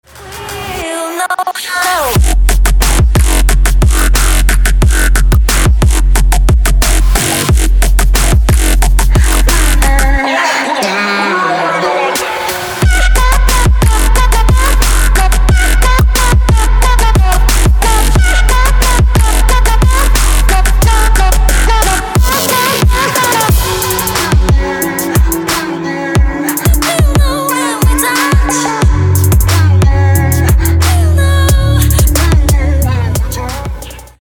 • Качество: 224, Stereo
Trap
club
electro